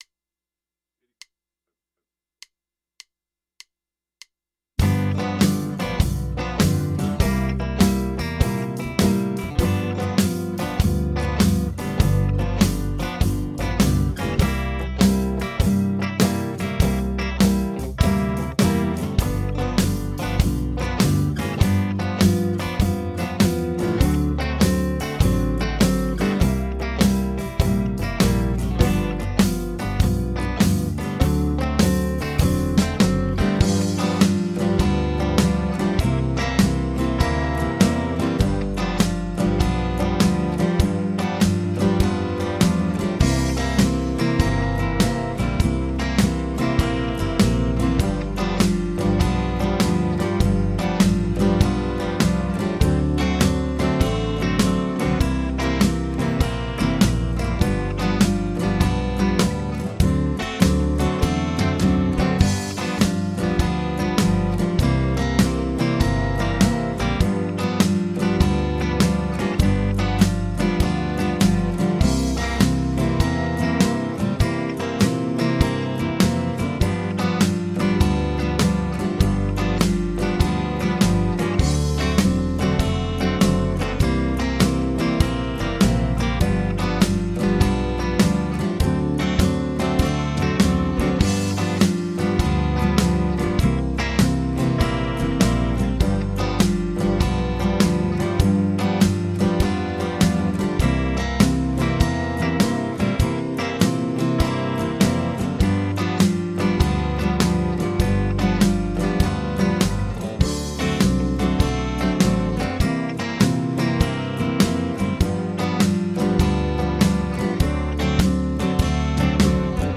Key: G
Tempo: 100